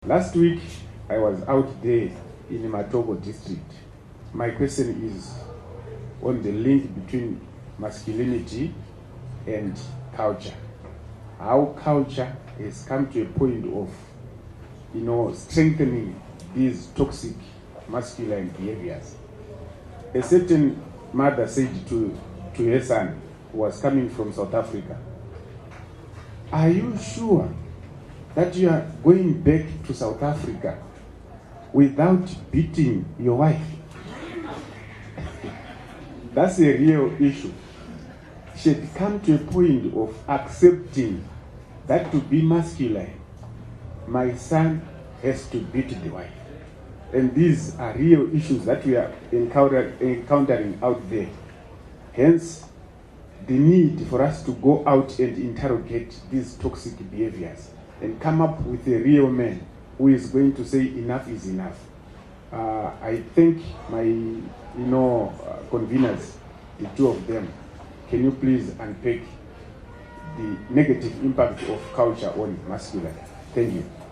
• Discussion